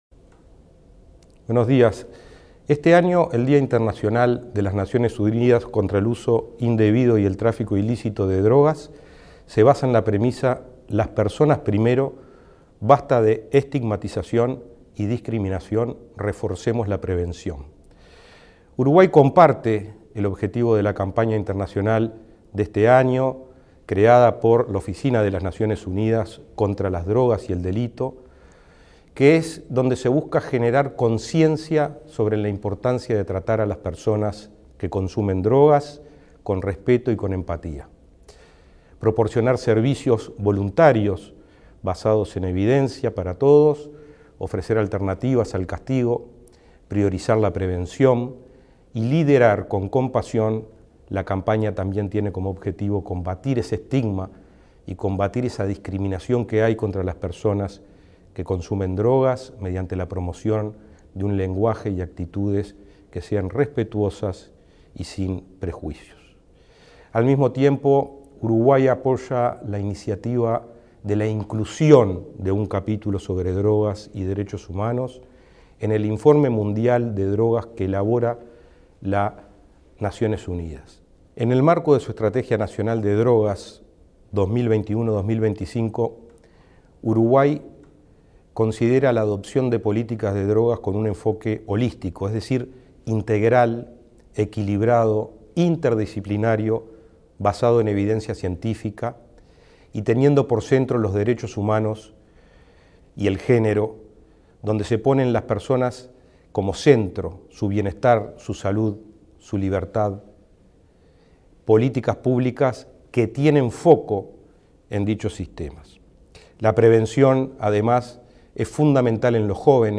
Mensaje de titular de la JND y prosecretario de la Presidencia, Rodrigo Ferrés, en ONU